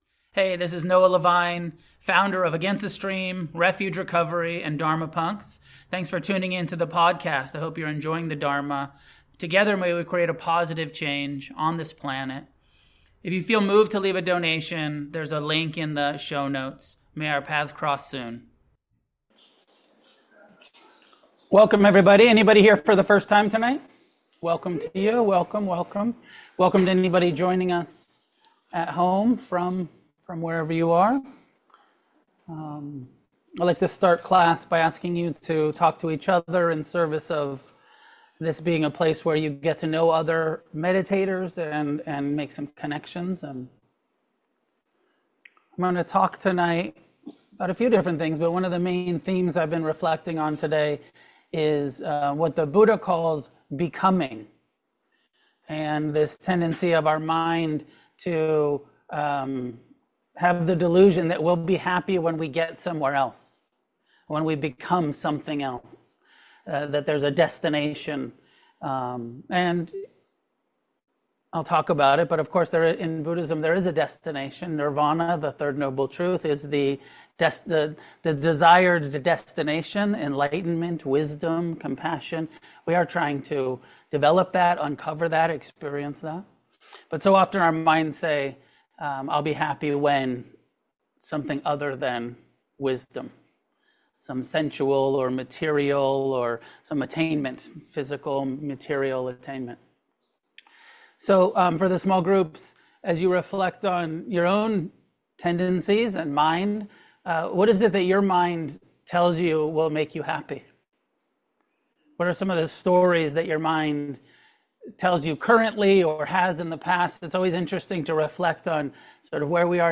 Dharma talks and guided meditations at Against The Stream Meditation Center.